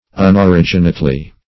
unoriginately - definition of unoriginately - synonyms, pronunciation, spelling from Free Dictionary Search Result for " unoriginately" : The Collaborative International Dictionary of English v.0.48: Unoriginately \Un`o*rig"i*nate*ly\, adv. Without origin.
unoriginately.mp3